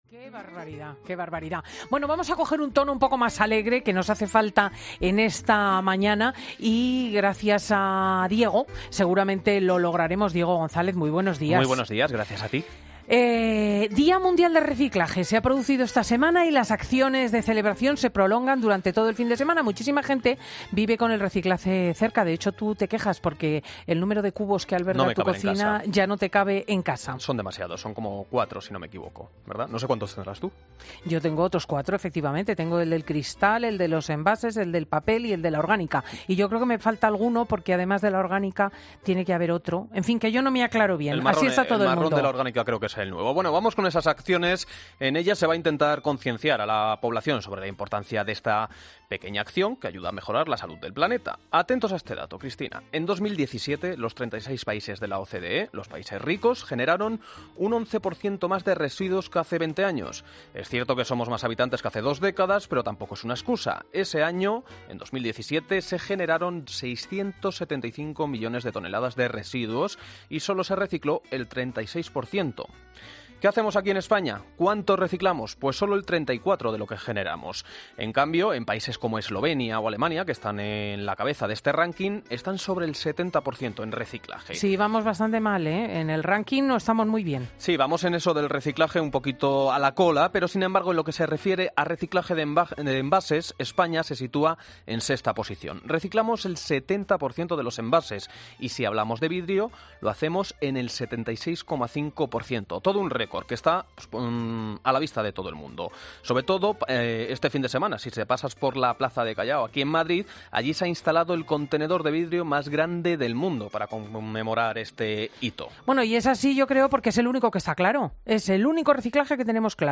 Escucha ahora a Cristina L. Schlichting en FIN DE SEMANA .